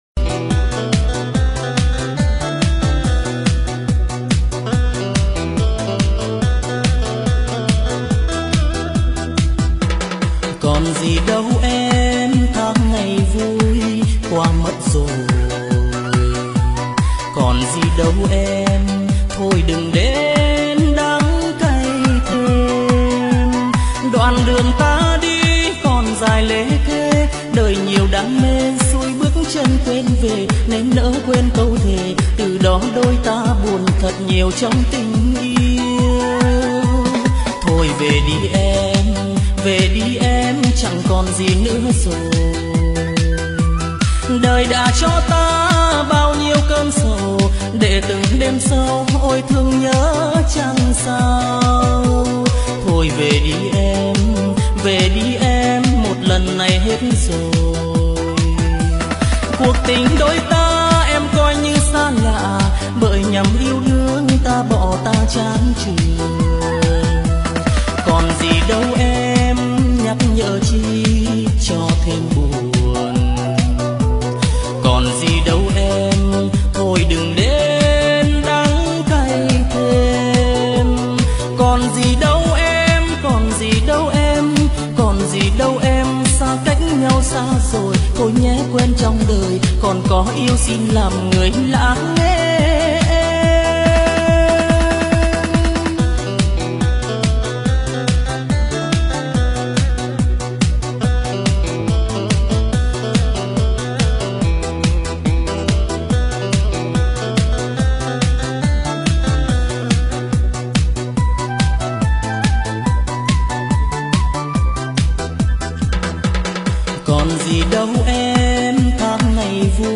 Trang chủ » Nhạc Thôn Quê »